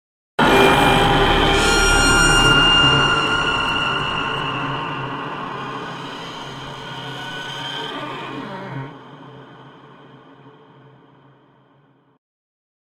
Category: brutal, horror
horror sound devil's voice